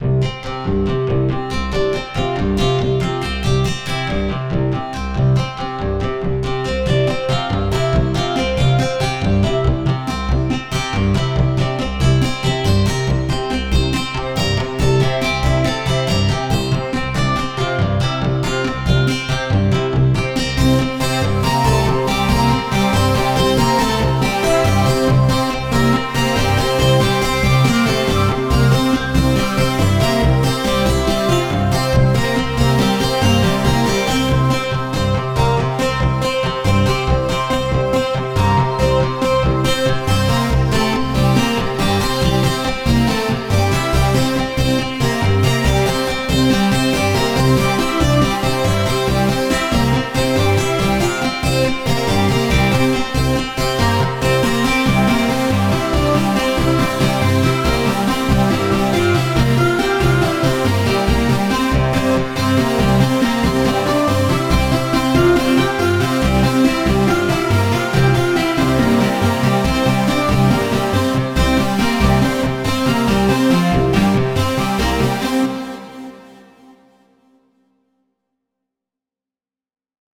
A kind puzzle like music, maybe